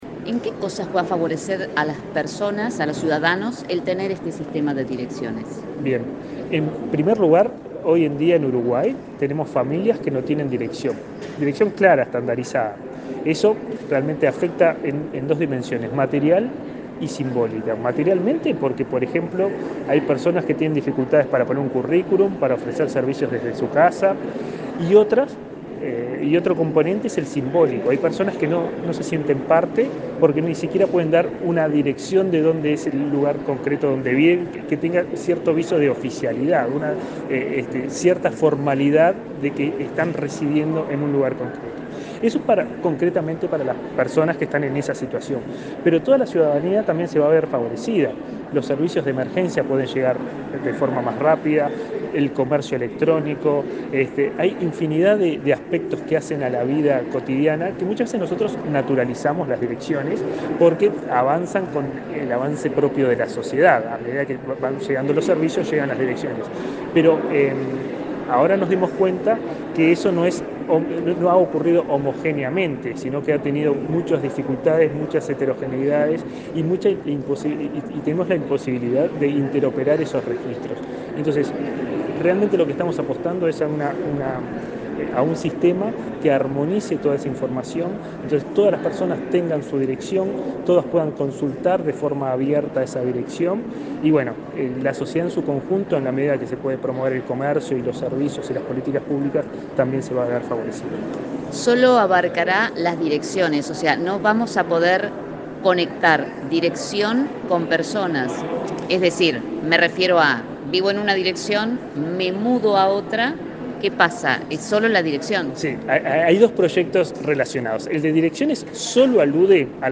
Entrevista al coordinador de Infraestructura de Datos Espaciales